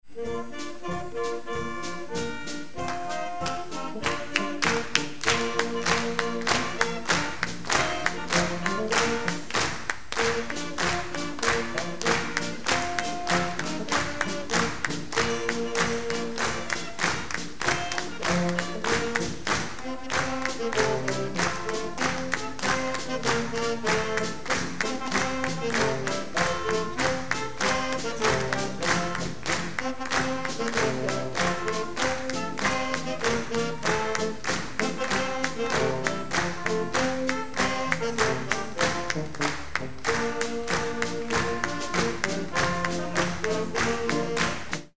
Konzert 2006 -Download-Bereich
-------Jugendorchester-------